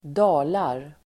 Uttal: [²d'a:lar]